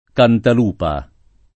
Cantalupa [ kantal 2 pa ] top. (Piem.)